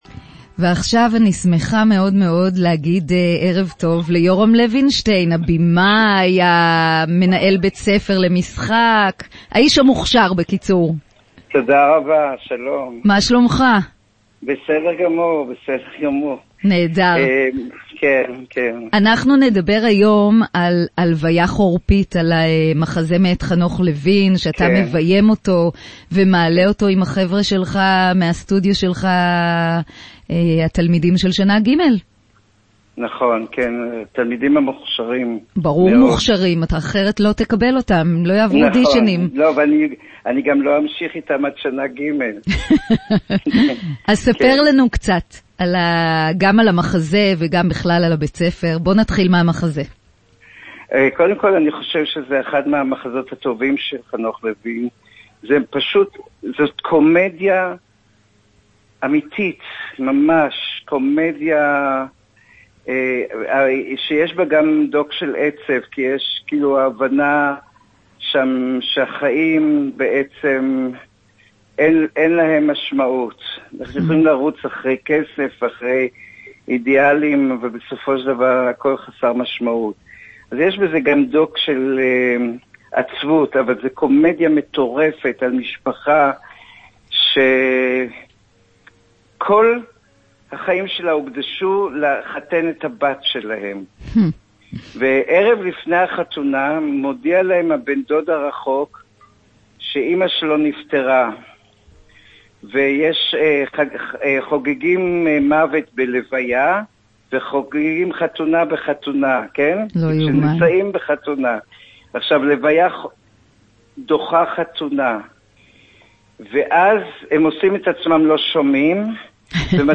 בראיון לרדיו רמה"ש